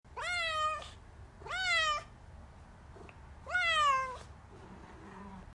Download Free Cat Sound Effects
Cat